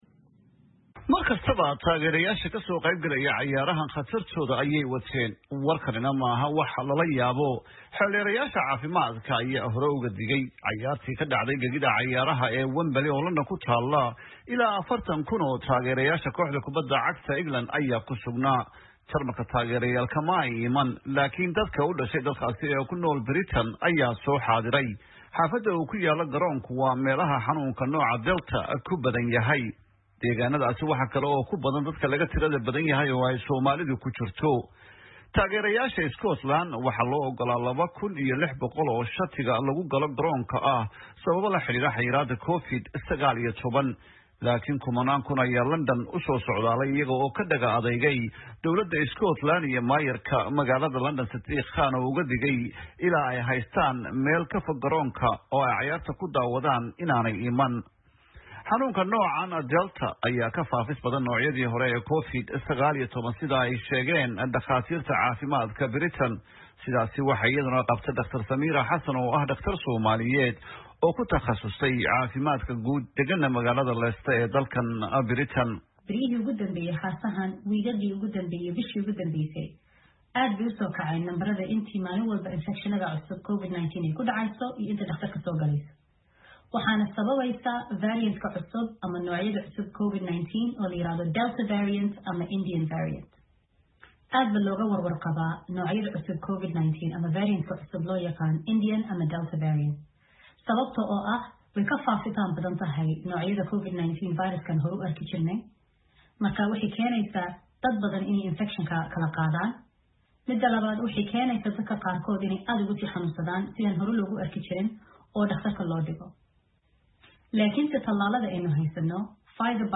LONDON —